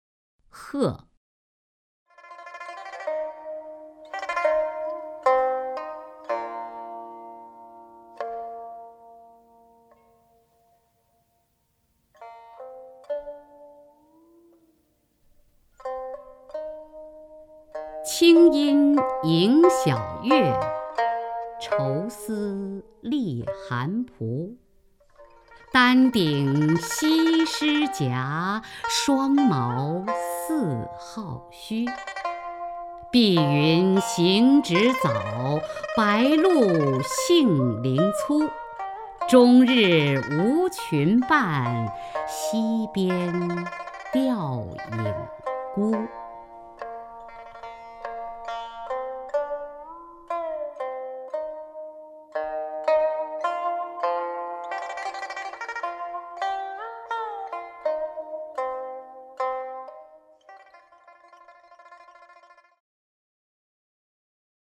雅坤朗诵：《鹤》(（唐）杜牧) （唐）杜牧 名家朗诵欣赏雅坤 语文PLUS